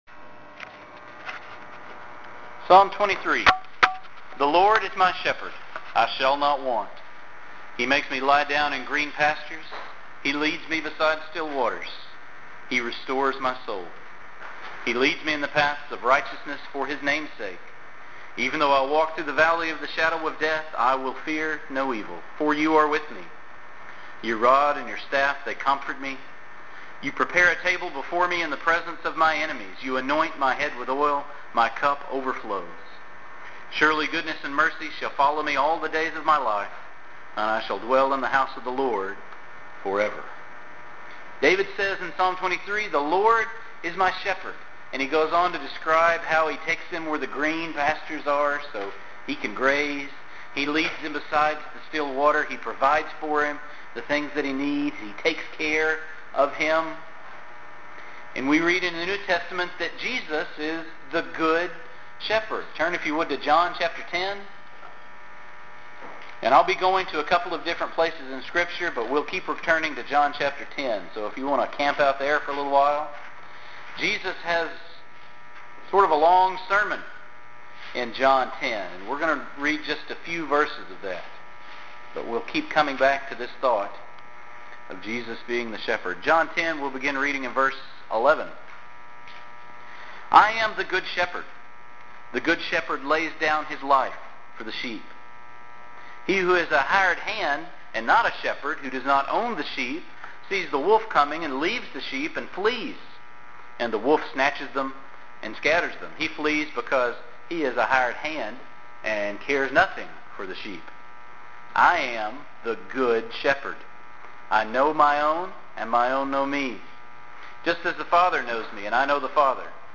Listen to the sermon Jesus, Sheep or Shepherd